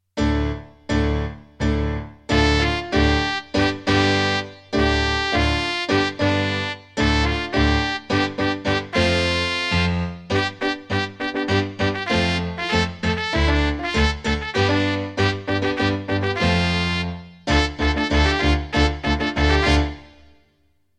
Just like in class, every recording will start with three piano chords to get ready before the melody starts and you can sing along (or simply follow along reading the score).  I used a different “instrument” from my keyboard’s sound library for each melody.